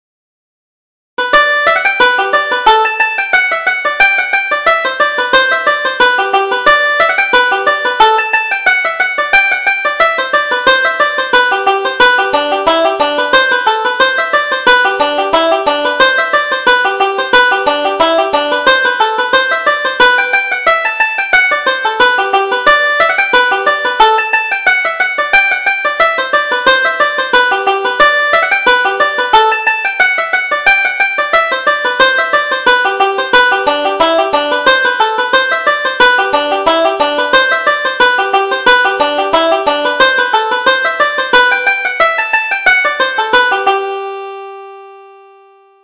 Reel: The Bottle of Porter